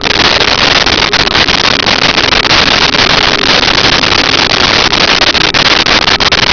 Sfx Amb Jungle Mix Loop
sfx_amb_jungle_mix_loop.wav